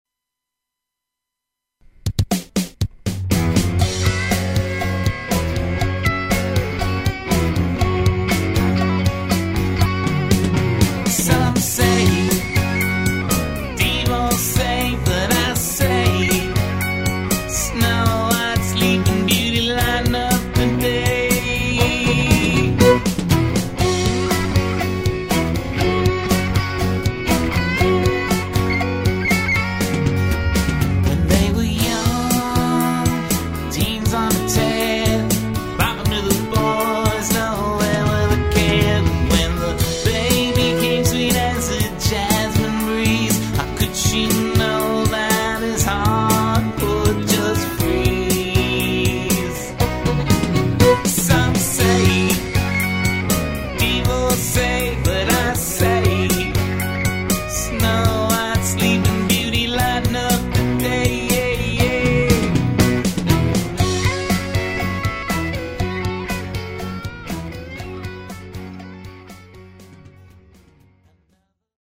violin
Fender Strat lead guitar
Neo-Americana music